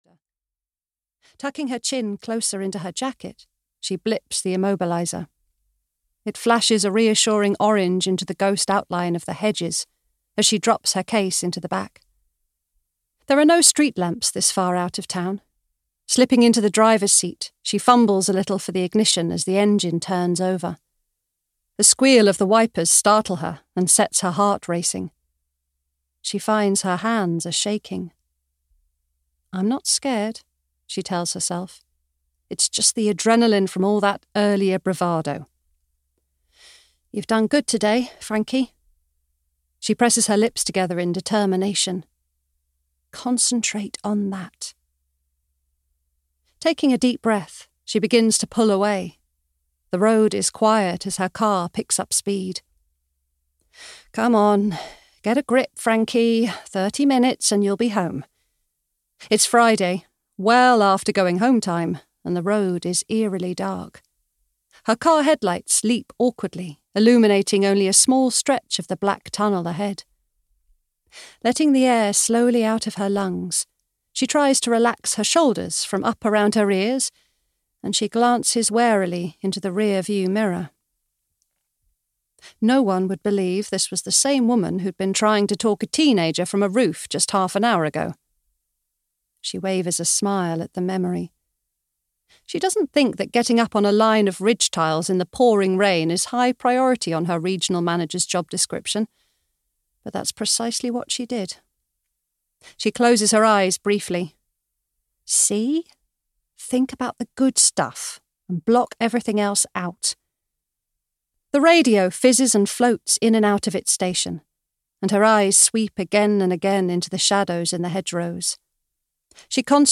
Keep My Secrets (EN) audiokniha
Ukázka z knihy